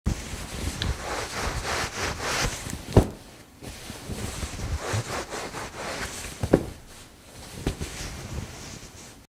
towel.wav